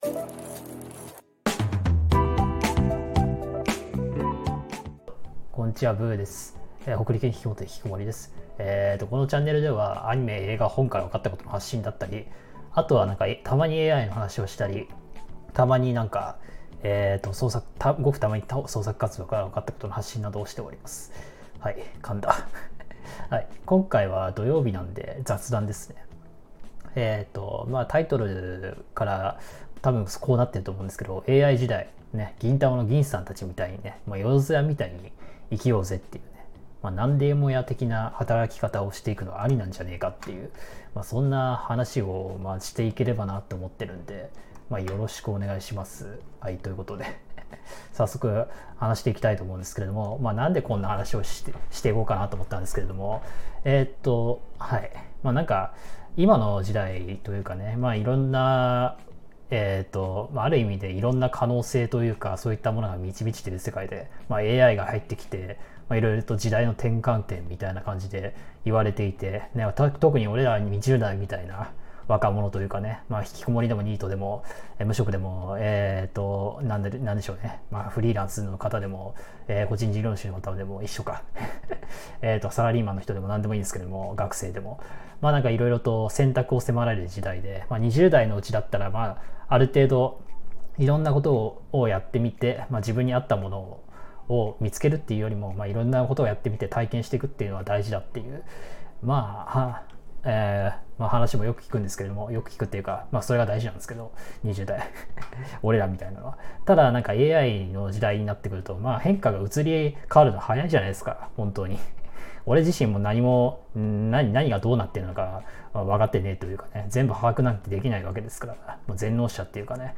【雑談】AI時代、銀さん達、万事屋みたいに生きようぜ ─なんでも屋的生き方─